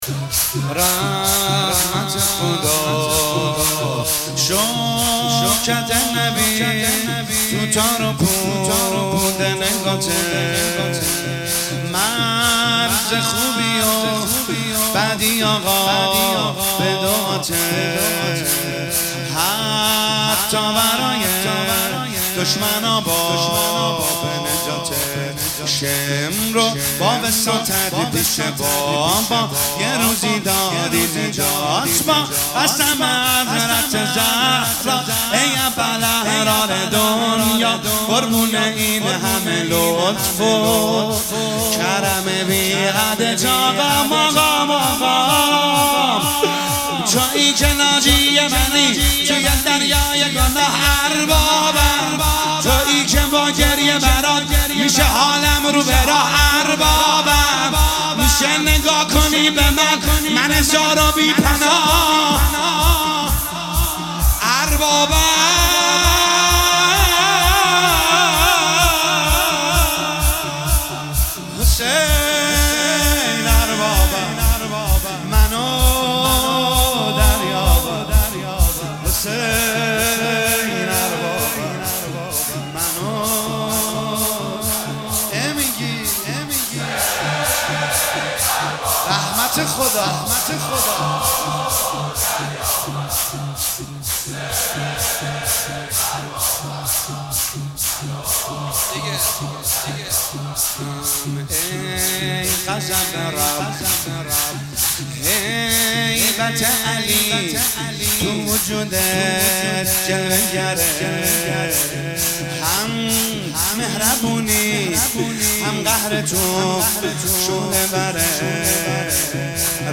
شب عاشورا محرم1401
شور رحمت خدا